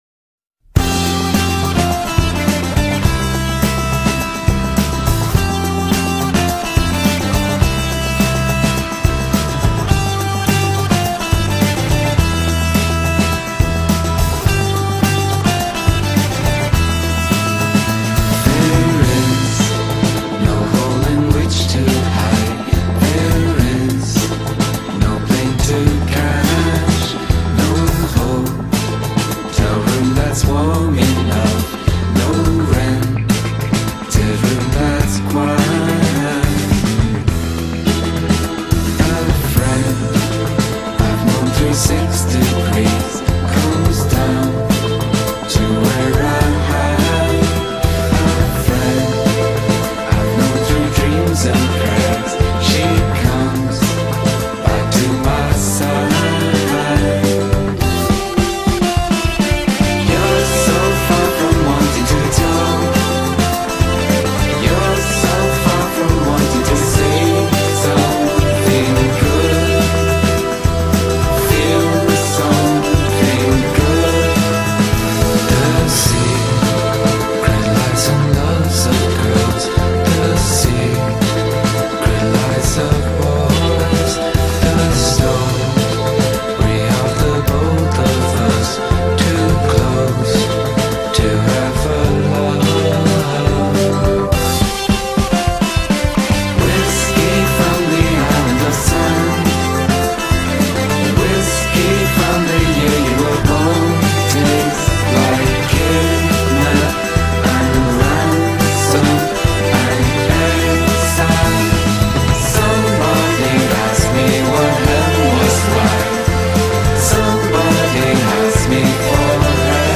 dreamiest infectious pop